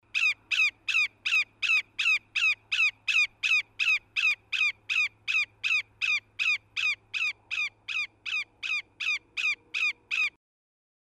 Greater Yellowlegs (Tringa melanoleuca)
Play MP3 Typical three-note call. Long Pond/Manuals, Aug 2003.